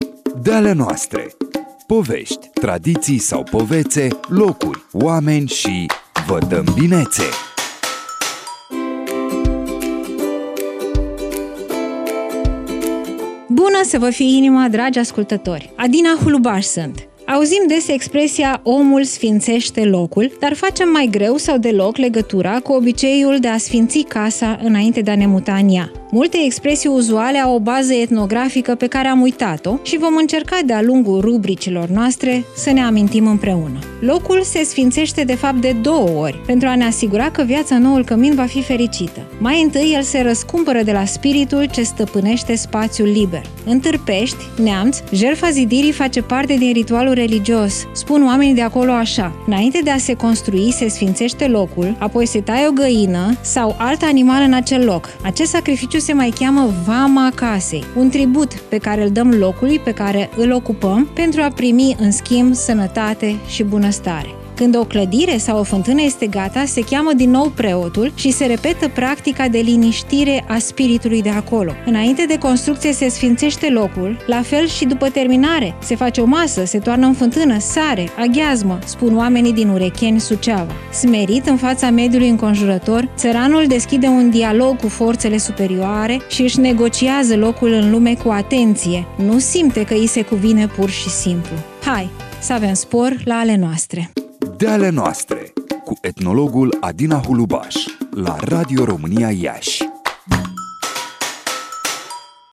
De-ale noastre”, rubrică nouă în programele Radio România Iași!